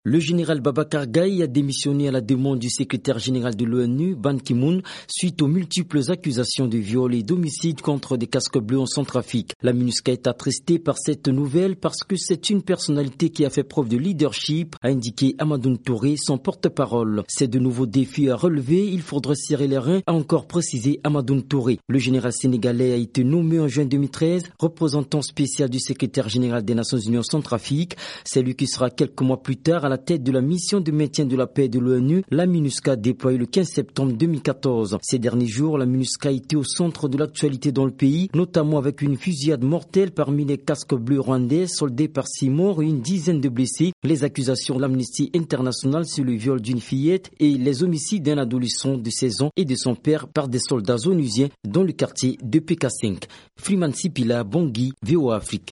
Compte-rendu